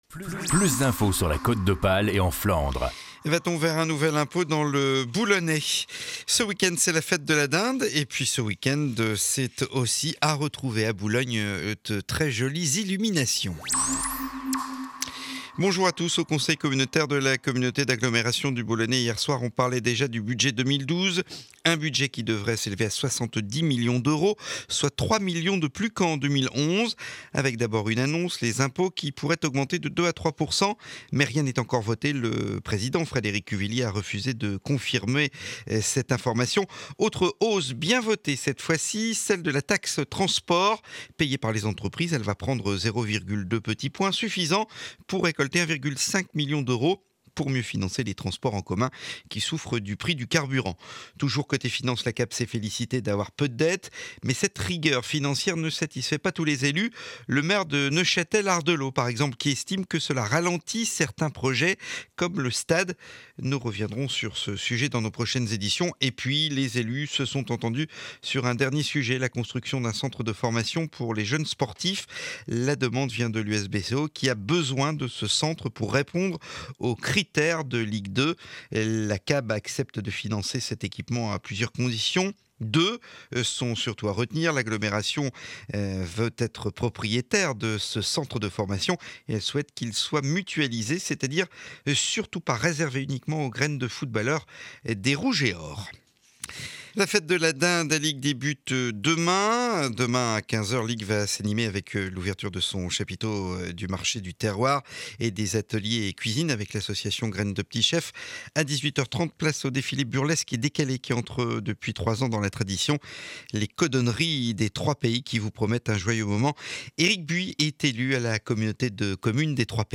Journal de 7h30 du vendredi 09 Décembre éditon de Boulogne.